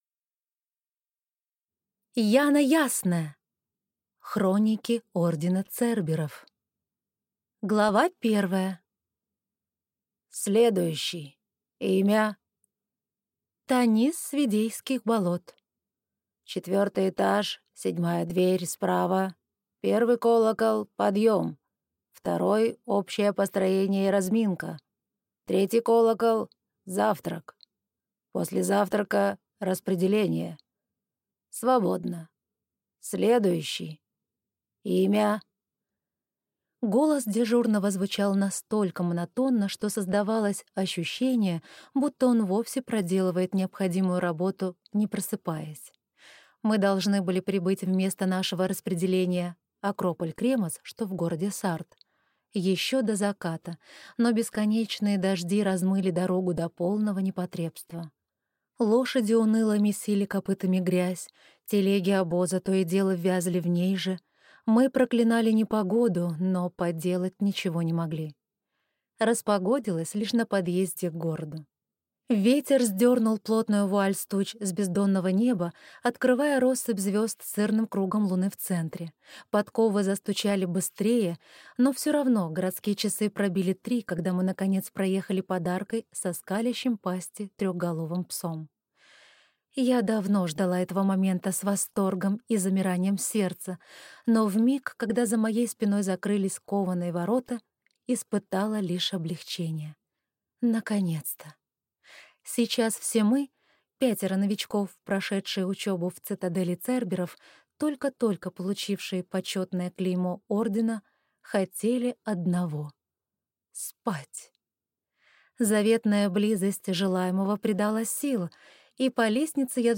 Аудиокнига Хроники ордена Церберов | Библиотека аудиокниг